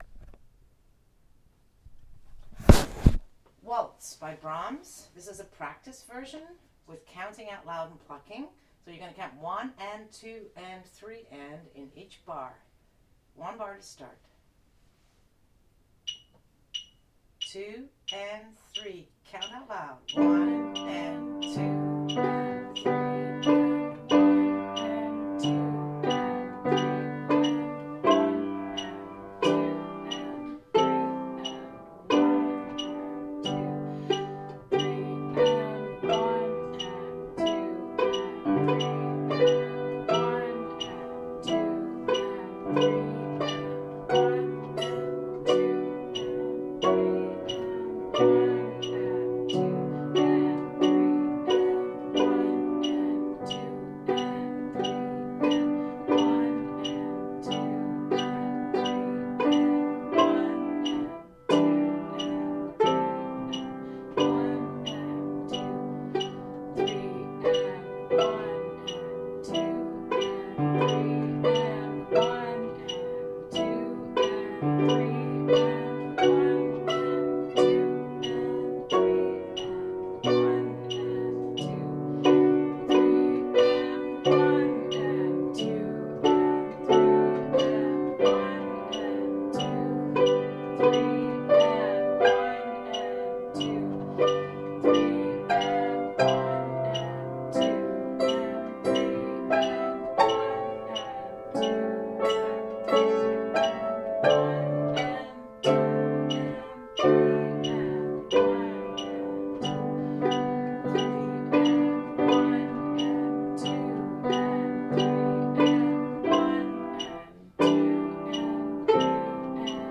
Waltz by Brahms (slow).mp3